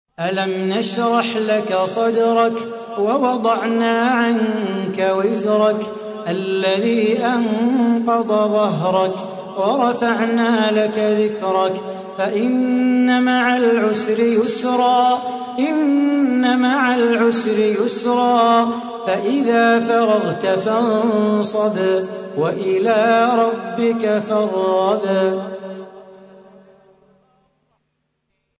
تحميل : 94. سورة الشرح / القارئ صلاح بو خاطر / القرآن الكريم / موقع يا حسين